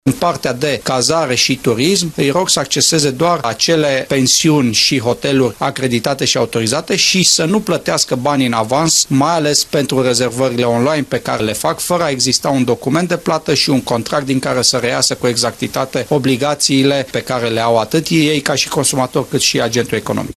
Sorin Susanu vine cu recomandări și pentru cei care nu sărbătoresc Crăciunul acasă: